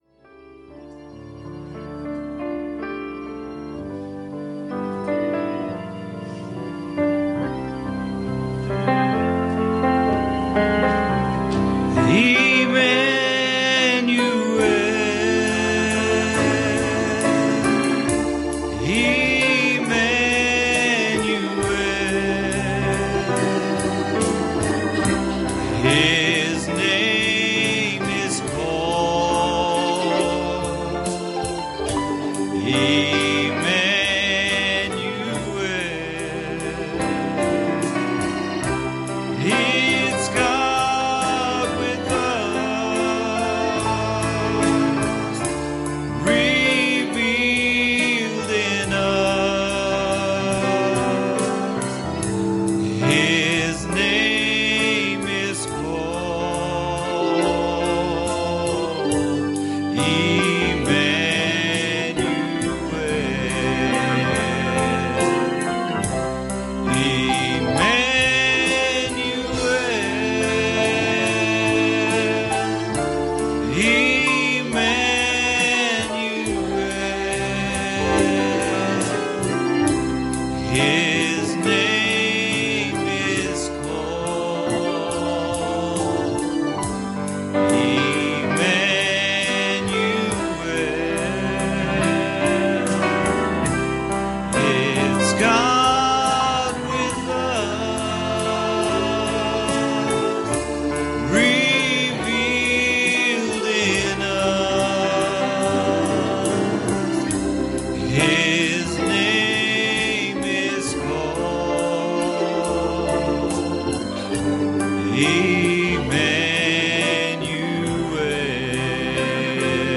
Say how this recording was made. Passage: Revelation 1;16 Service Type: Sunday Evening